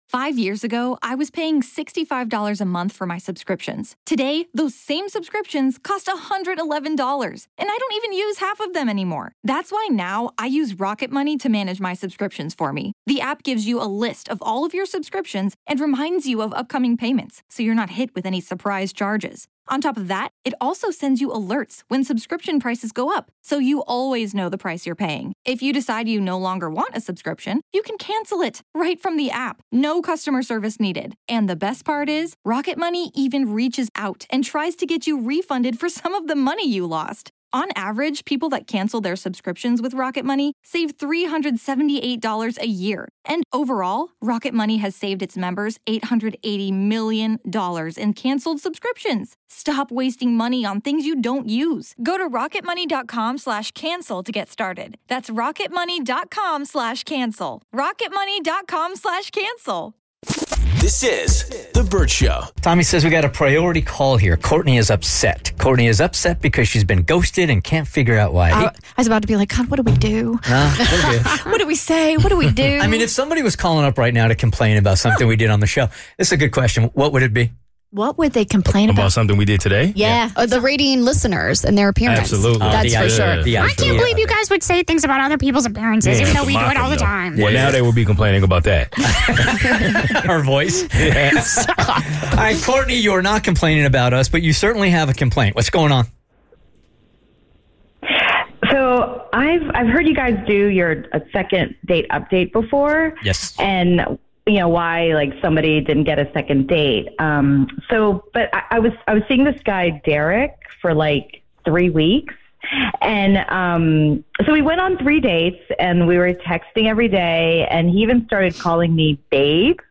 Why did he ghost her after their THIRD date? She called in looking for answers and we’re gonna find them!